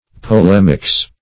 Polemics \Po*lem"ics\, n. [Cf. F. pol['e]mique.]